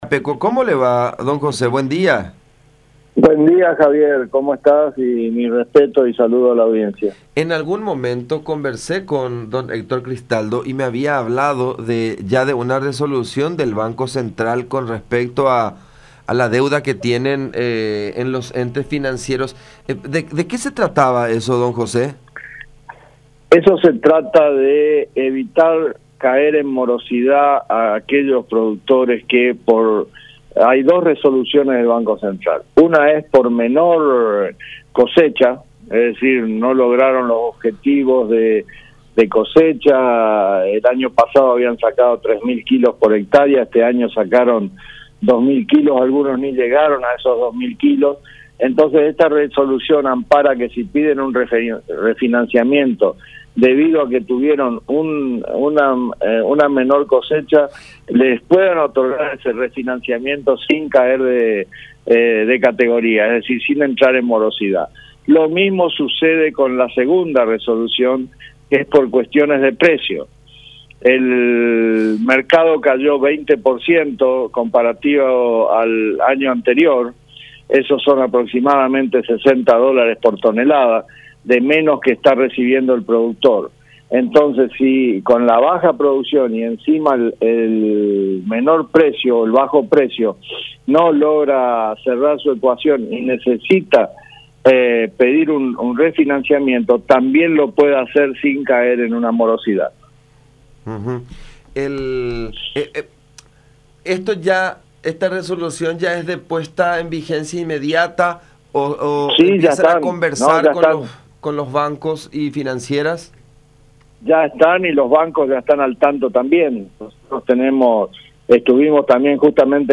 “Fue una charla muy productiva la de ayer con el Presidente de la República la que tuvimos ayer. Los bancos ya están al tanto de estas resoluciones”, comentó en conversación con La Unión.